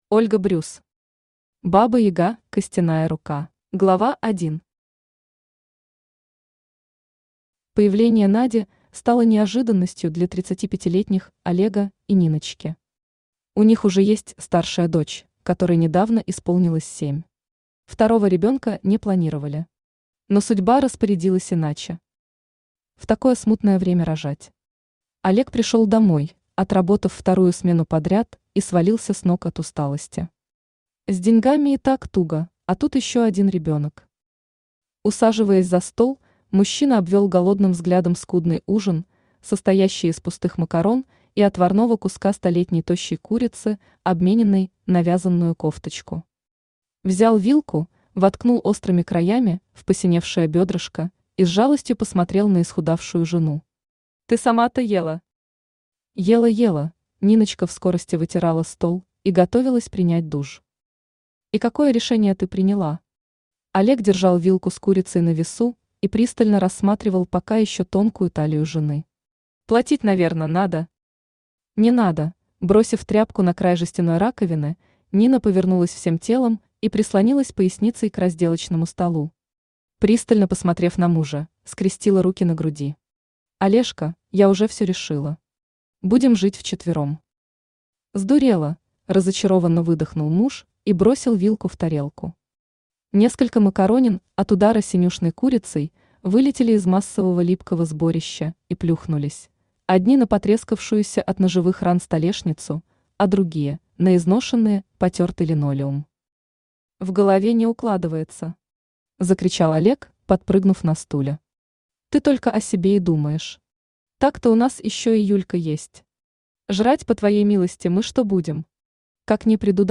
Аудиокнига Баба-яга костяная рука | Библиотека аудиокниг
Aудиокнига Баба-яга костяная рука Автор Ольга Брюс Читает аудиокнигу Авточтец ЛитРес.